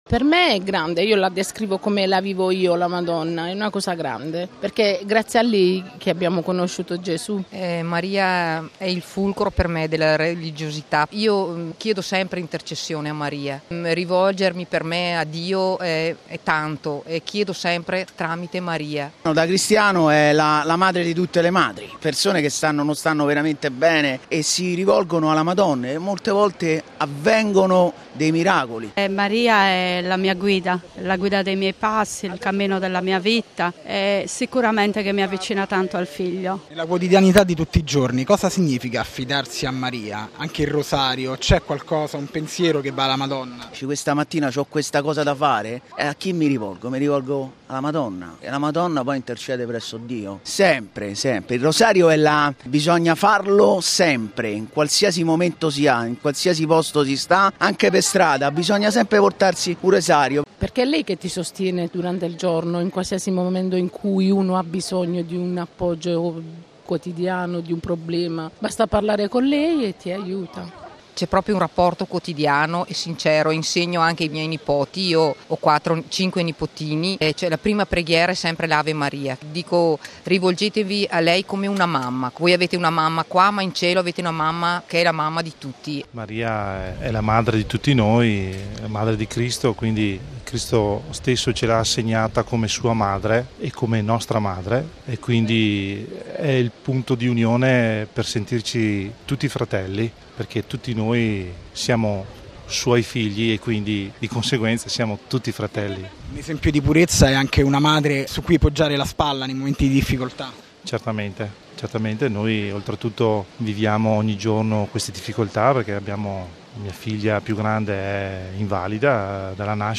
Alcune migliaia i fedeli presenti in Piazza San Pietro per l'Angelus dell'Assunta in una stupenda giornata di sole.